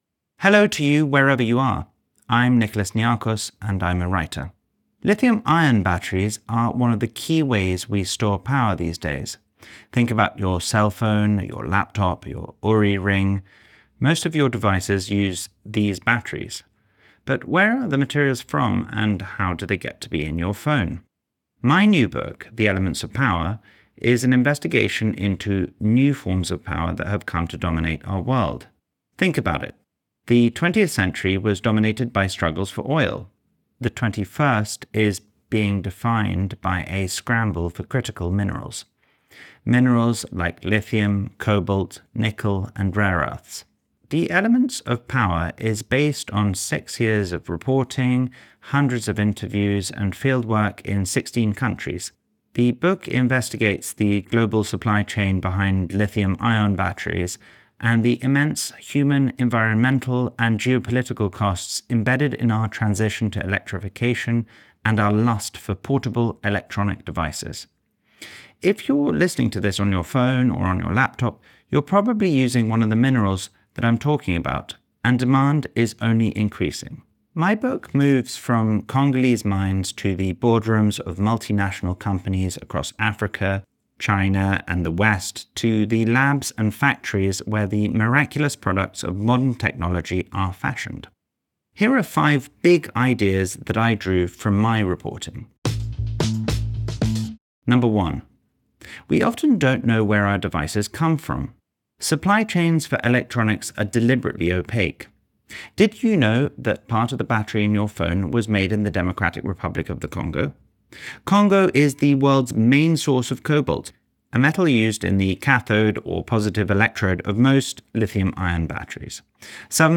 Enjoy our full library of Book Bites—read by the authors!—in the Next Big Idea App: